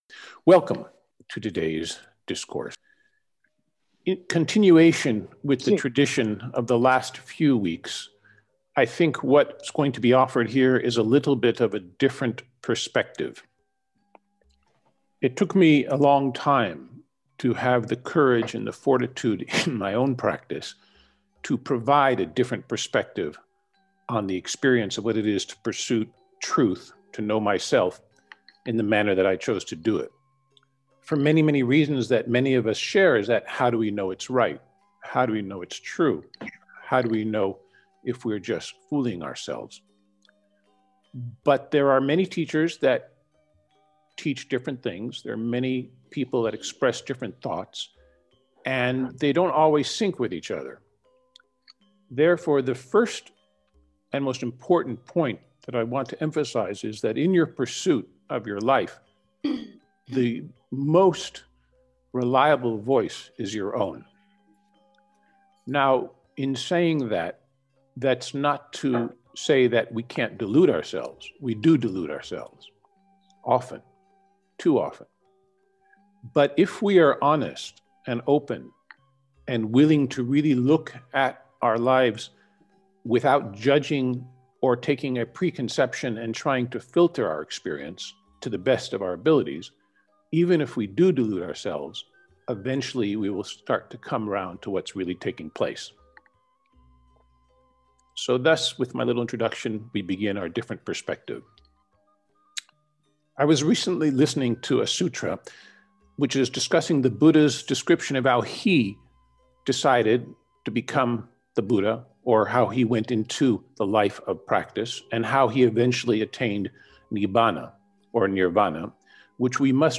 Path to Wisdom :: Conversation
Sunday 11 October 2020 we continued our comparative discussion on Buddha Dharma Philosophy. Specifically we continued with the Satipatthana Sutta, Karma and further discussion practical applications of the BuddhaDharma in daily living.